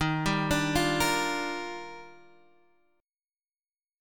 D# Major 9th
D#M9 chord {x 6 5 7 6 6} chord